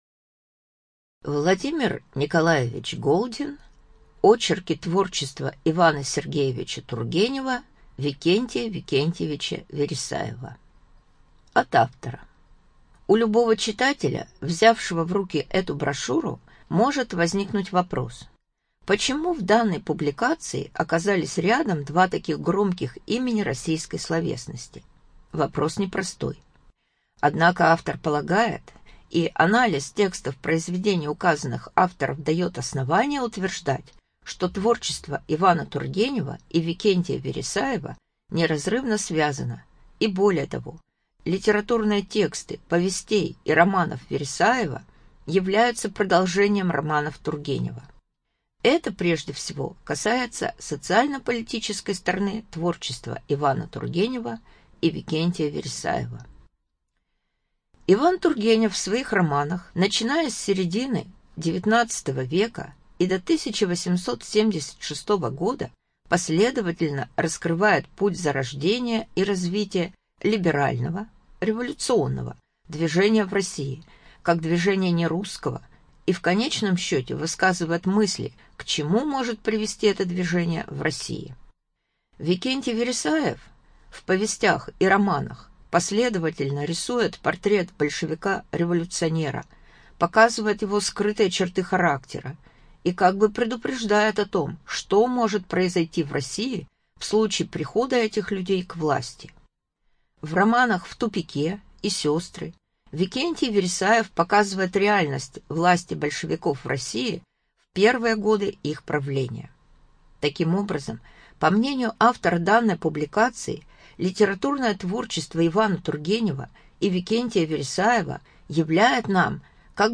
Студия звукозаписиСвердловская областная библиотека для слепых